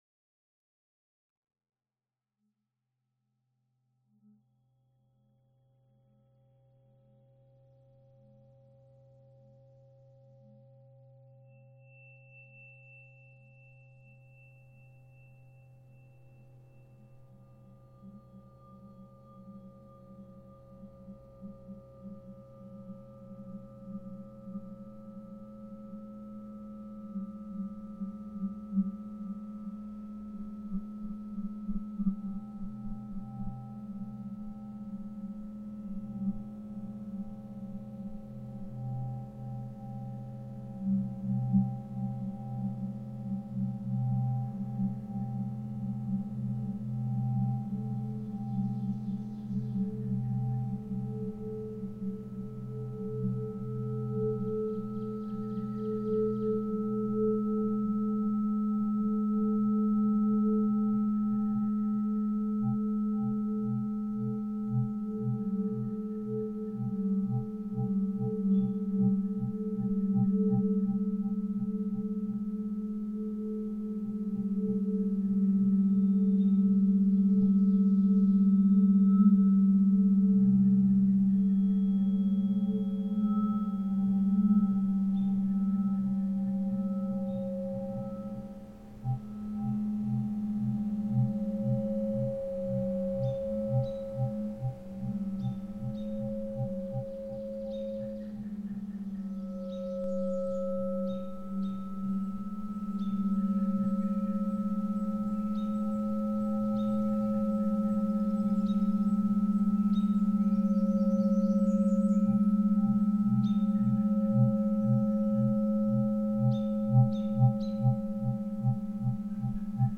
Sound Selected audio works for objects and installations. (headphones recommended)